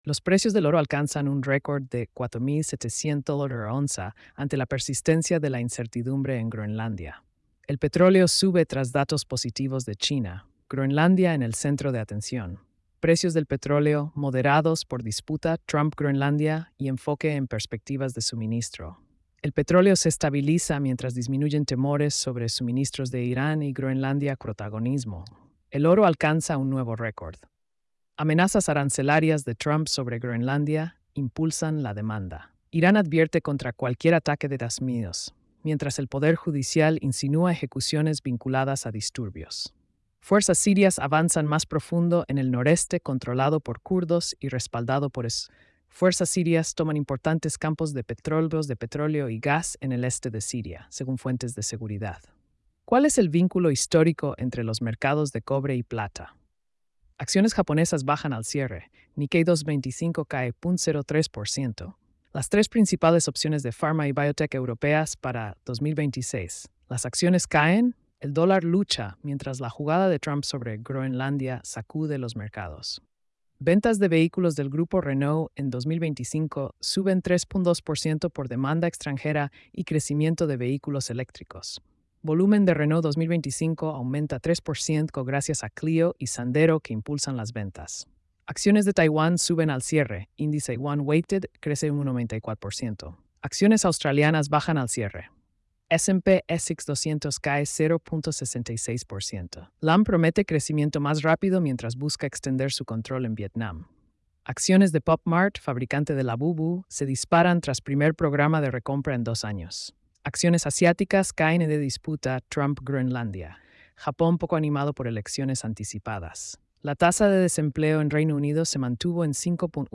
🎧 Resumen Económico y Financiero.